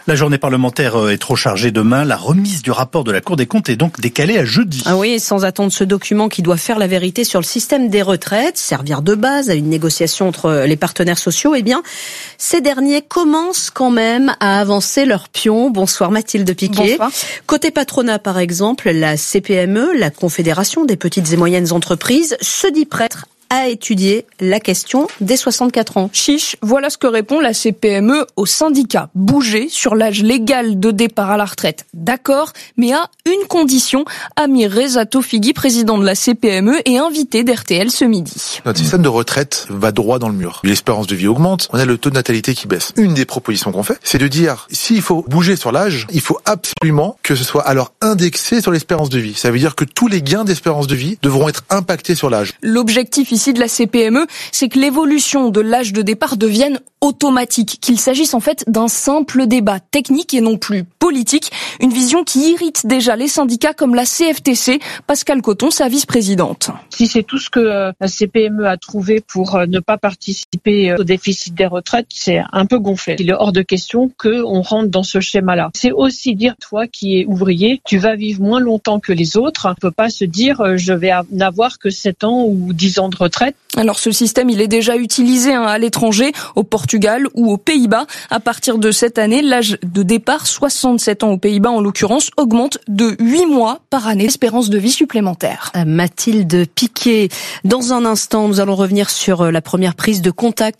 Retrouvez ci-dessous des extraits choisis de leurs interventions respectives :